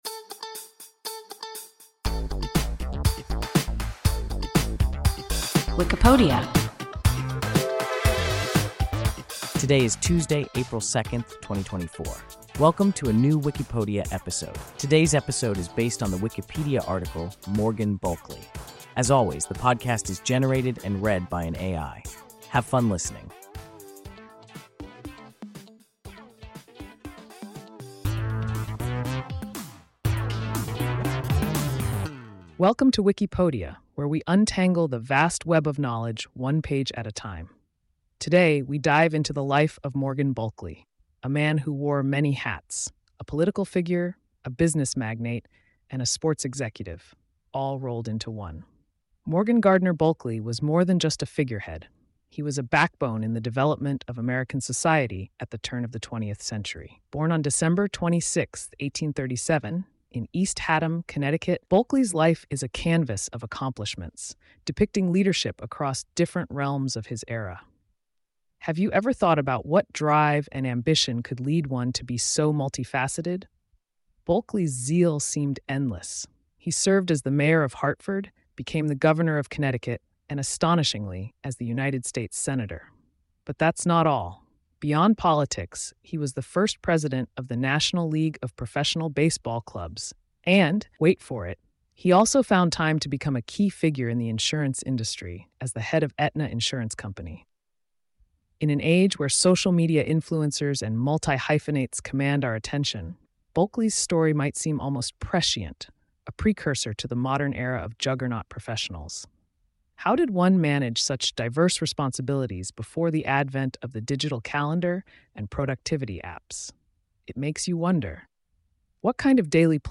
Morgan Bulkeley – WIKIPODIA – ein KI Podcast